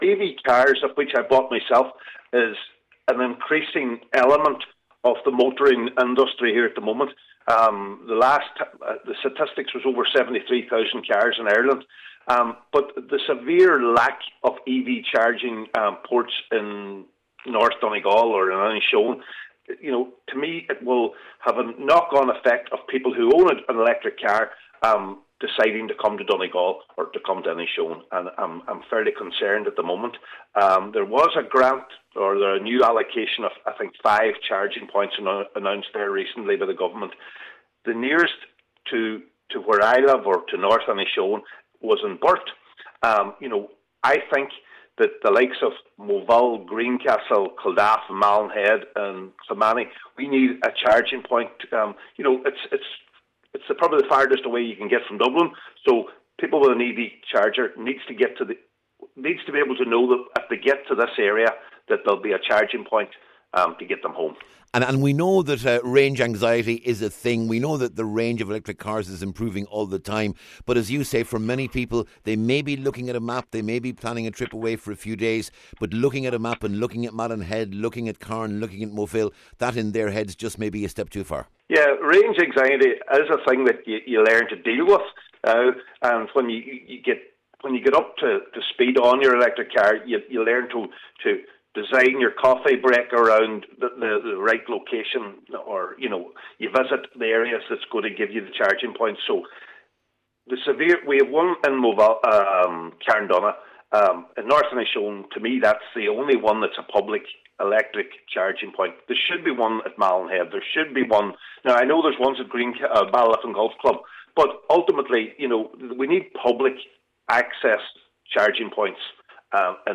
Cllr Farren told a recent Inishowen Municipal District he fears the lack of charging points is putting the north of the peninsula at a distinct disadvantage……………..